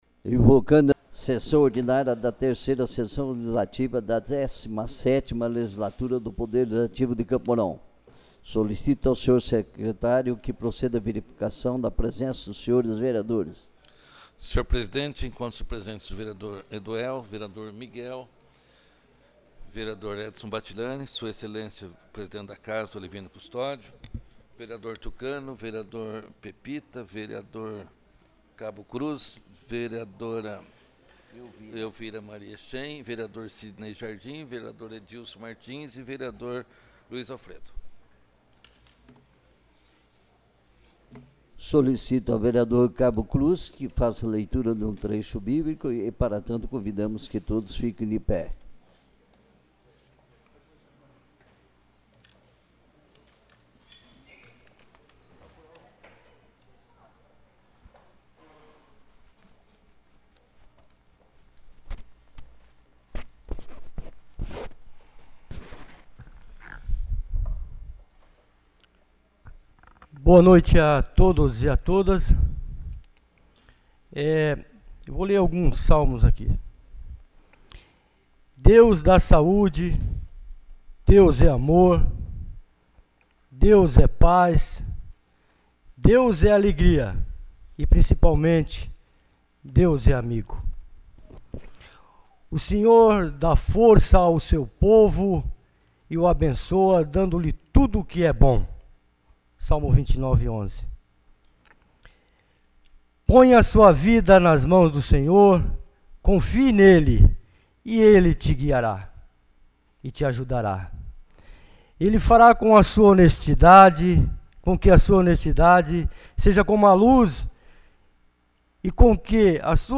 28ª Sessão Ordinária